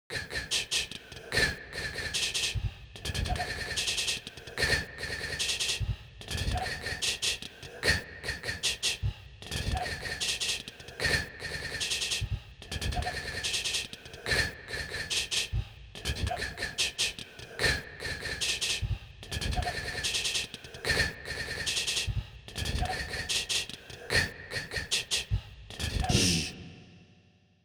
phonemes-demo.aiff